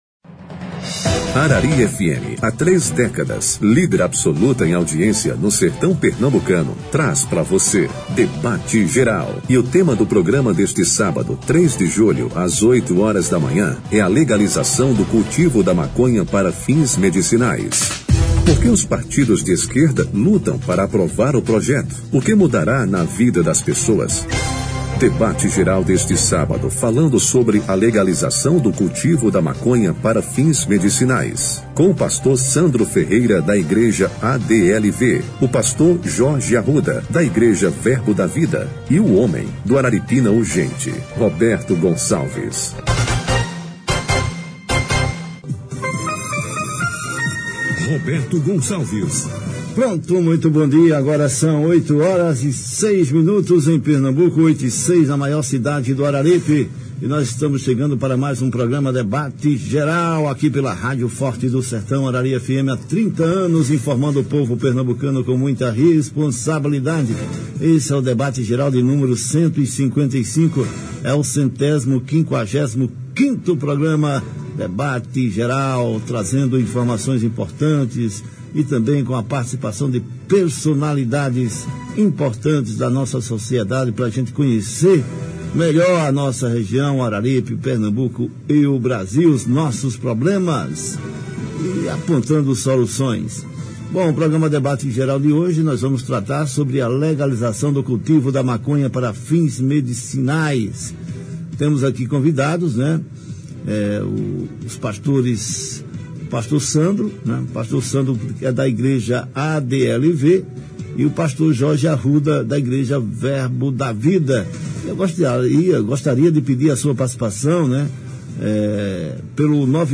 O debate qualificado